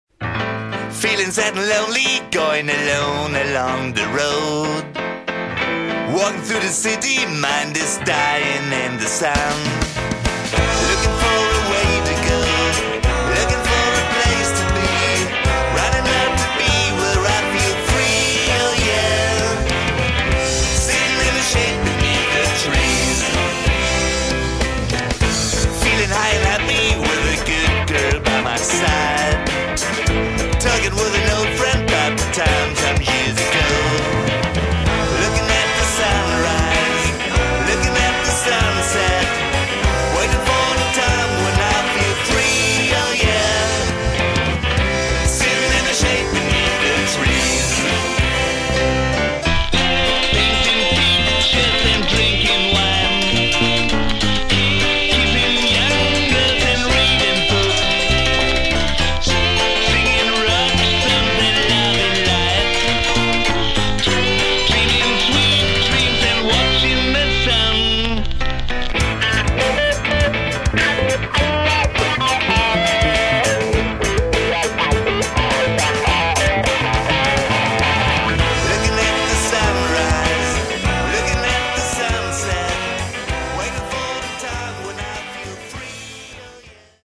ld-voc., p.
voc., rh-g.